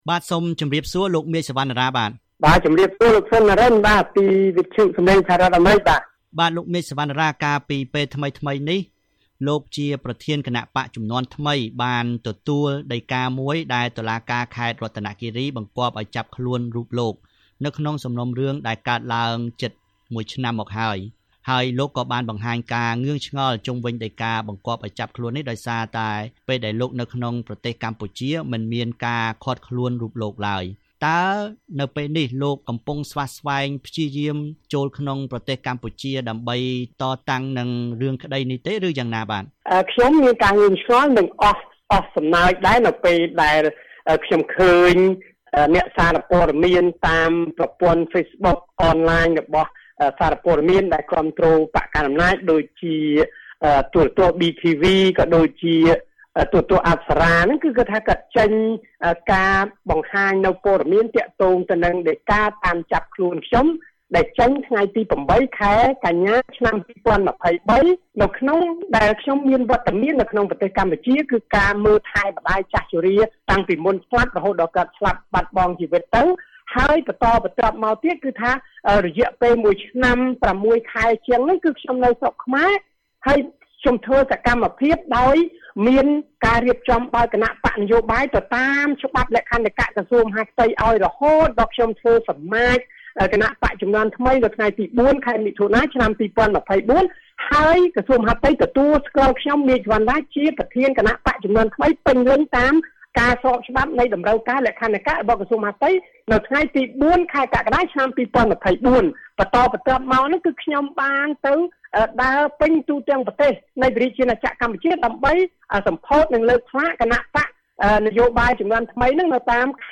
នៅក្នុងបទសម្ភាសន៍ជាមួយវីអូអេ